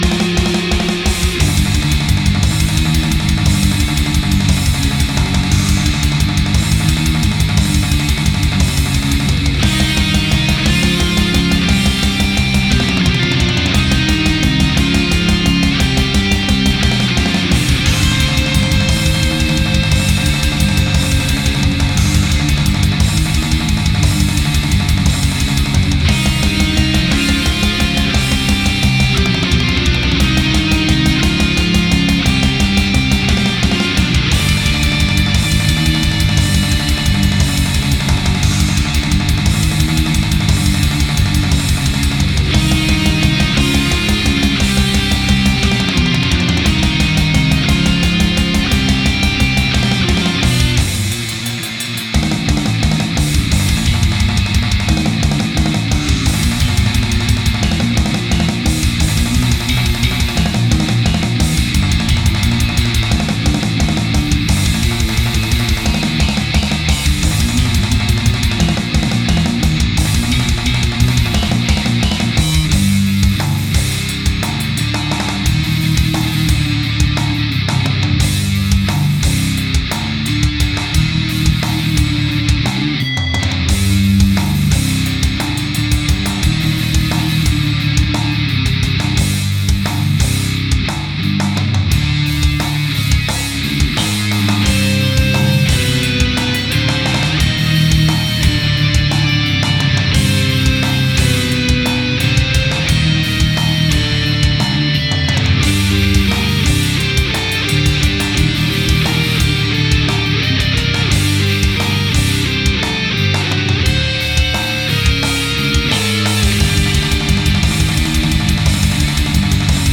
Гитары
Бас-гитара, программирование ударных